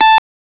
un son bref.